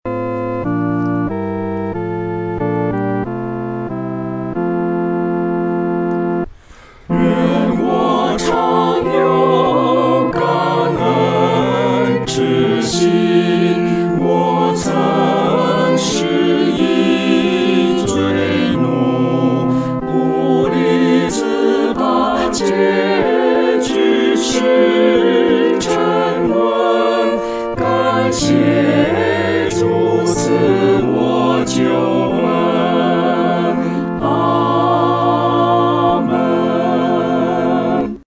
合唱（四声部）